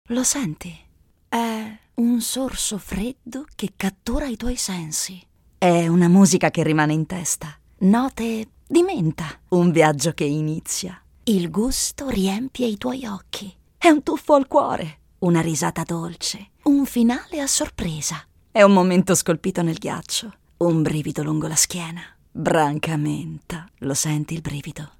Sensuale, caldo